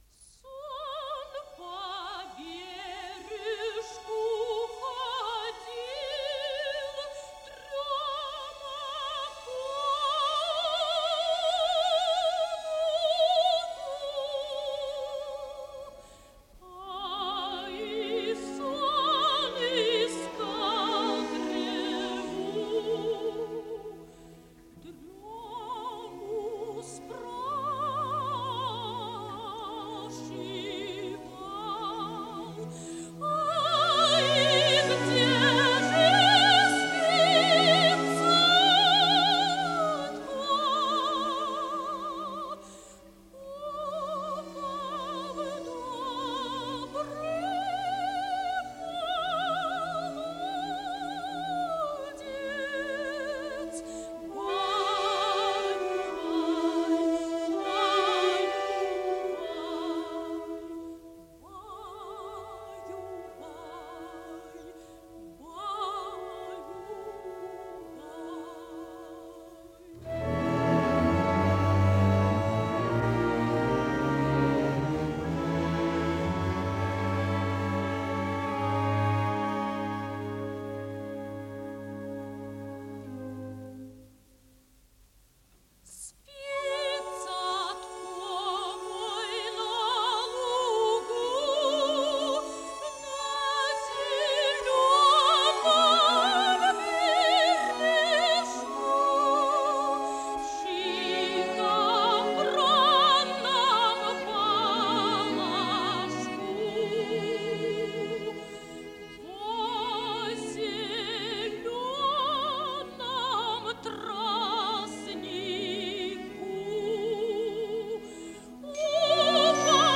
Жанр: Opera
В исполнении отечественных певиц.